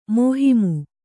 ♪ mōhimu